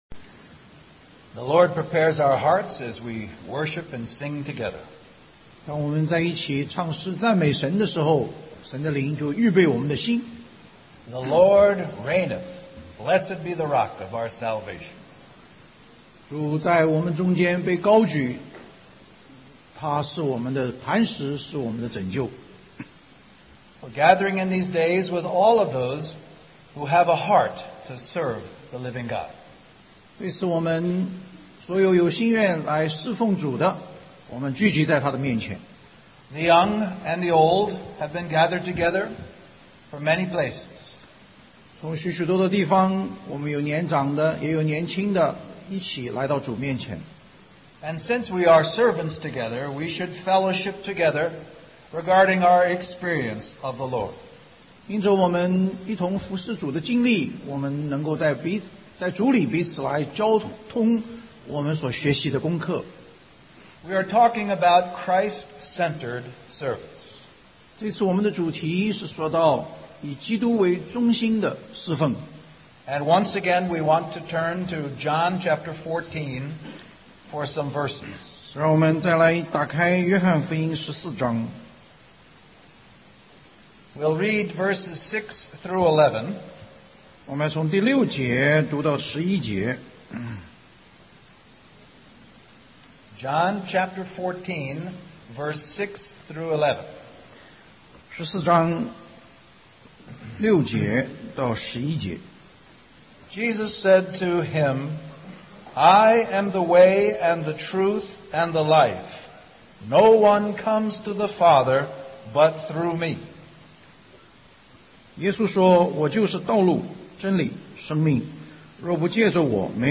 2009 Special Conference For Service, Singapore Stream or download mp3 Summary Our brother shares from the conference theme of "Christ Centered Service".